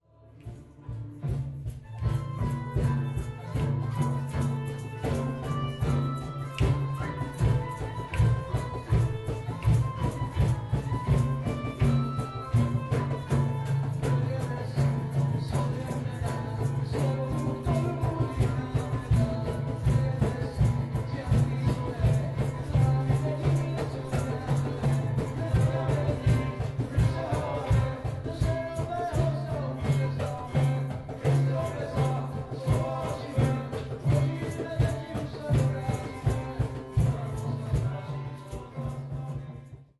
Kto sa na to cítil, mohol sa chytiť jednoduchých hudobných nástrojov – hrkálky, či cajónu (kachonu). Nasledovala energická hudobná časť, kde sa do hudby účastníci mohli zapojiť alebo tancovať.